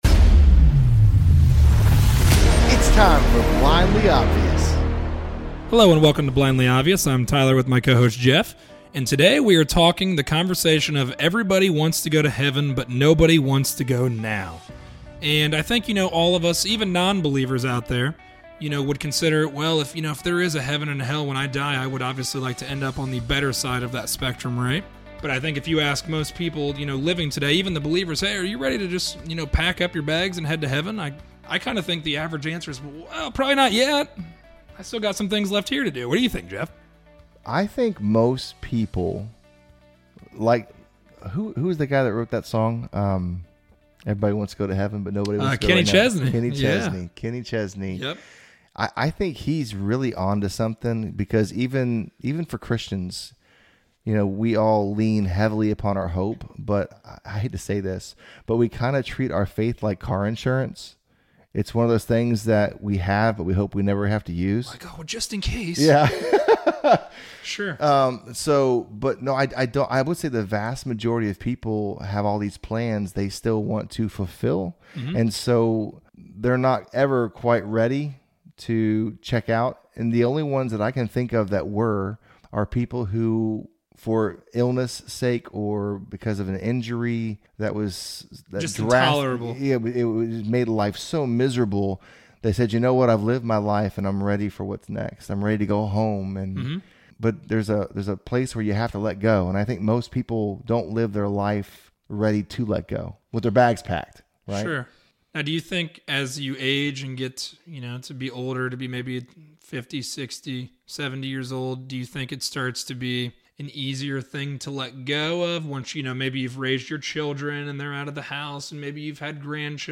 A conversation on where we’re going when our time is up. Are our bags packed and ready, or is it never quite “now”?